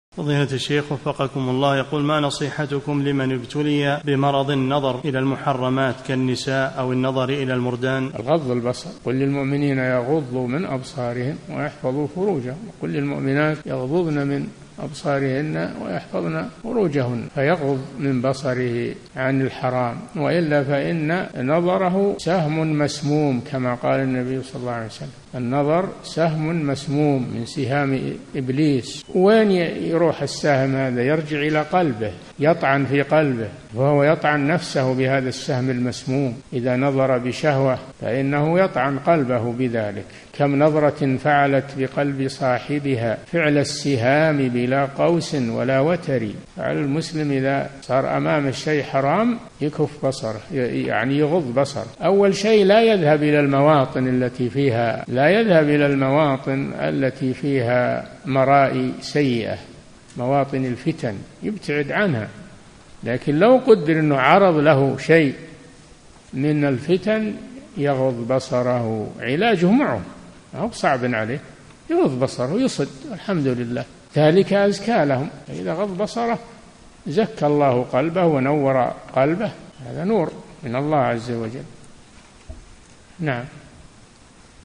ملف الفتوي الصوتي عدد الملفات المرفوعه : 1